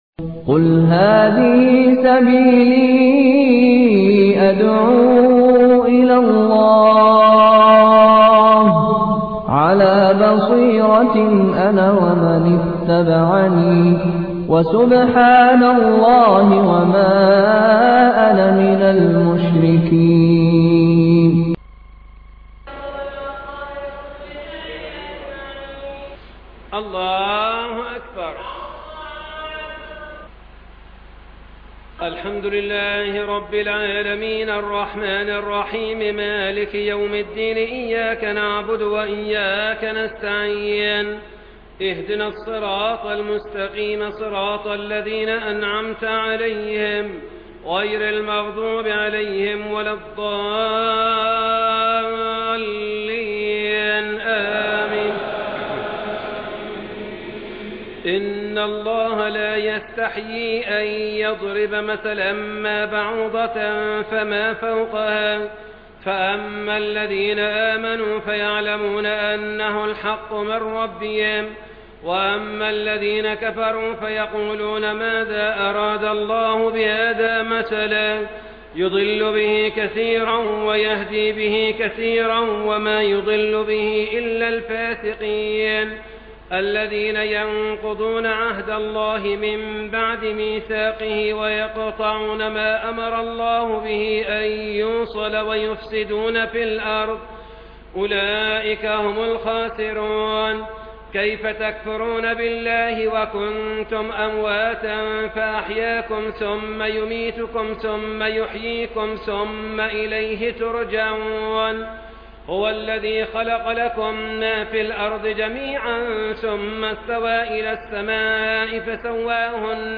صلاة التراويح من المسجد الأقصى (ليلة 1 رمضان) 1432 هـ - قسم المنوعات